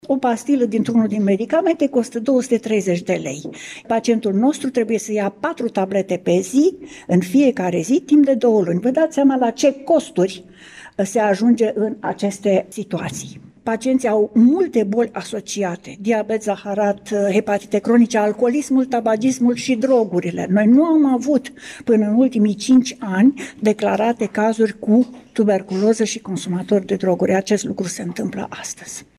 Astăzi, într-o conferință de presă organizată cu prilejul Zilei Mondiale de Luptă împotriva Tuberculozei